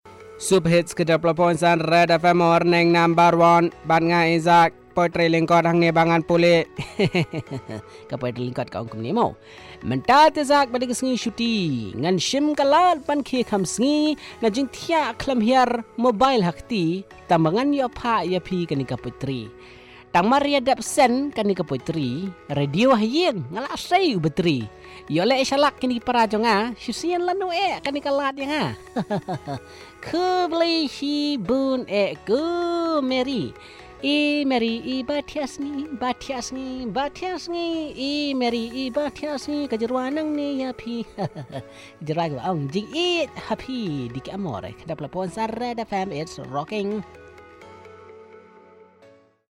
Short poem from a listener